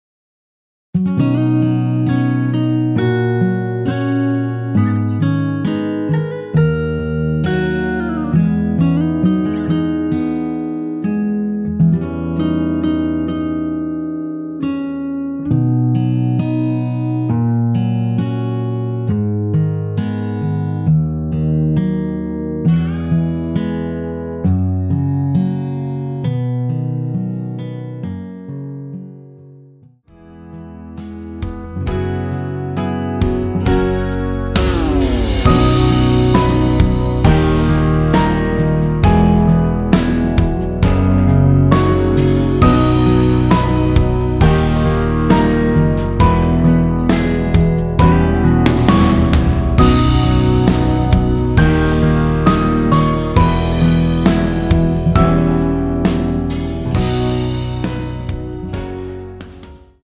원키에서(-1) 내린 MR 입니다.
◈ 곡명 옆 (-1)은 반음 내림, (+1)은 반음 올림 입니다.
앞부분30초, 뒷부분30초씩 편집해서 올려 드리고 있습니다.